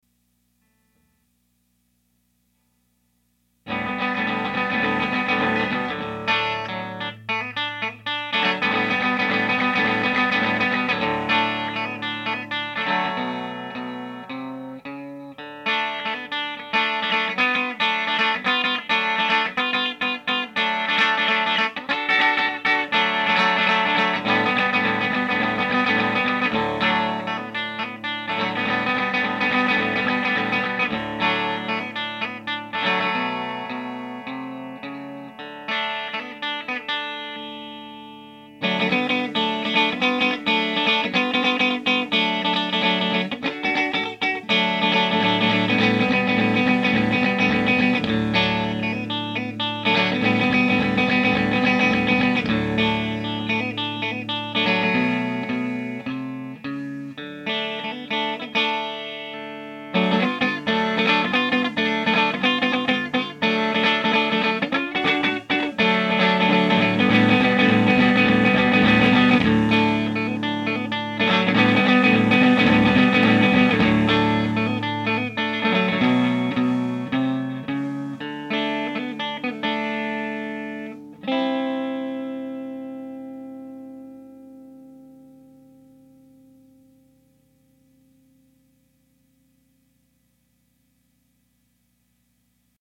Everything is set the same for both of them, totally dry, no reverb, no post processing. Don’t mind my multiple mistakes, the rattle is the snare drum that I didn’t disengage the snare from, sorry.
Furrian, it starts on the bridge pickup, then both, then just the P-90.